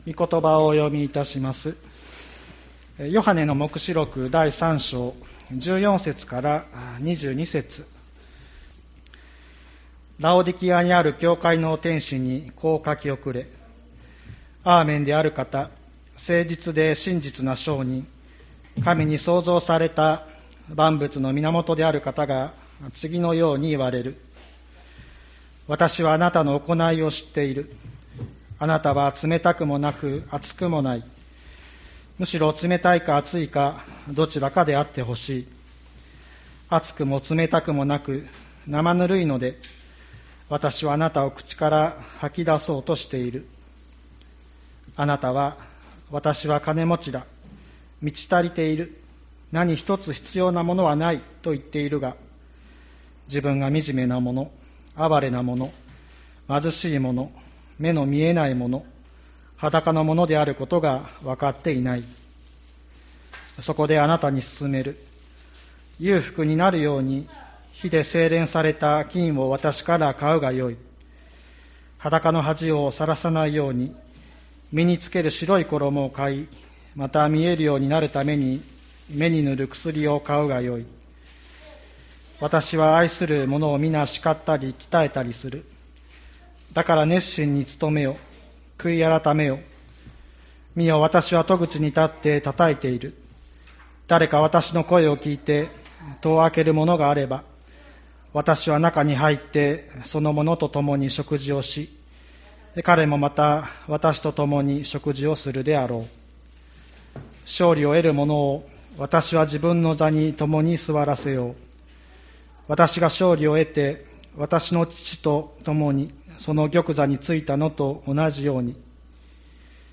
2020年06月28日朝の礼拝「キリストを迎える者は誰か」吹田市千里山のキリスト教会
千里山教会 2020年06月28日の礼拝メッセージ。